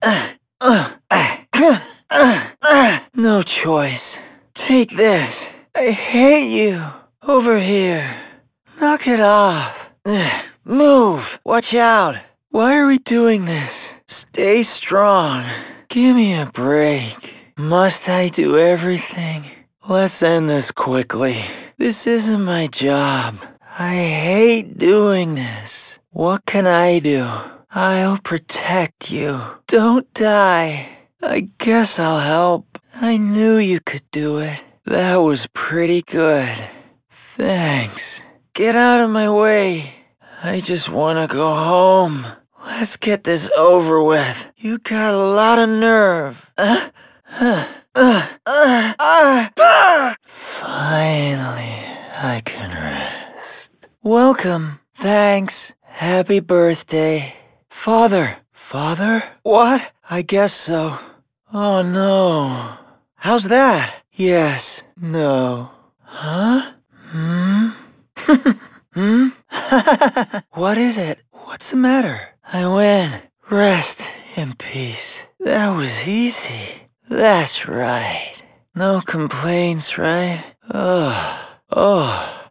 battle quotes, shop + story dialogue, etc